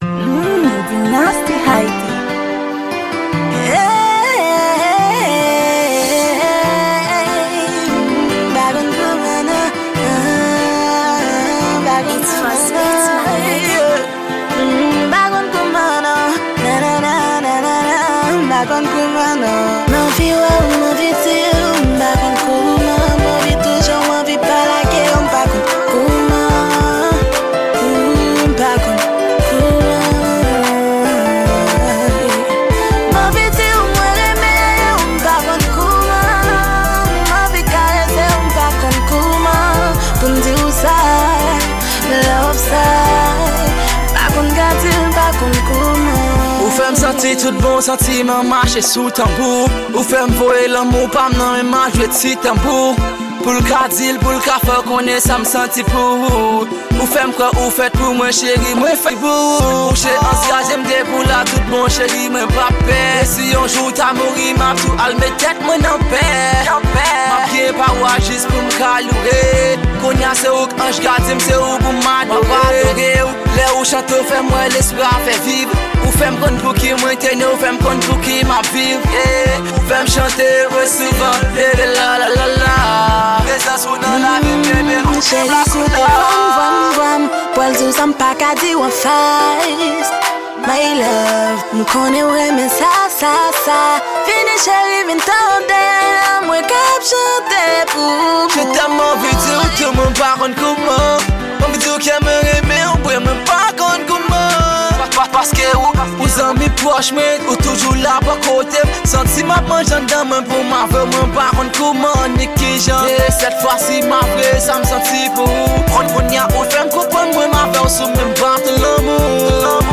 Genre: Rap-RnB.